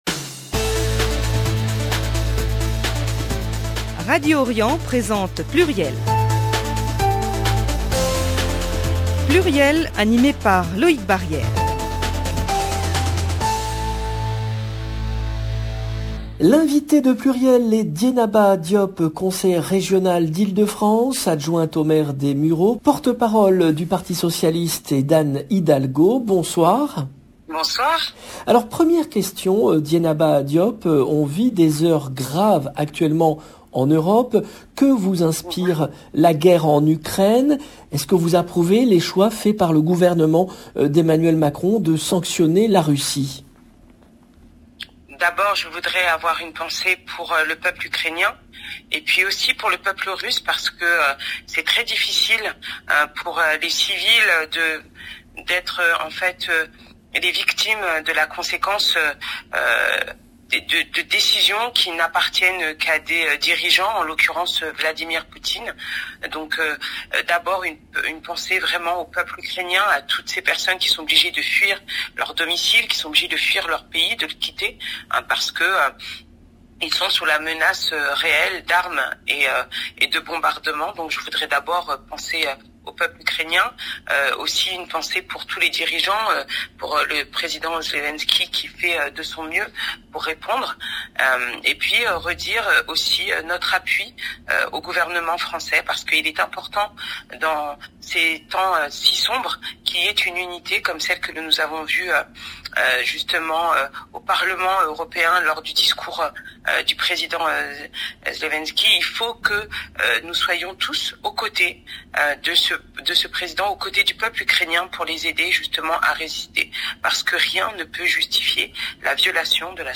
le rendez-vous politique du mercredi 2 mars 2022 L’invitée de PLURIEL est Dieynaba Diop , conseillère régionale d’Ile-de-France, adjointe au maire des Mureaux, porte-parole du Parti socialiste et d’Anne Hidalgo.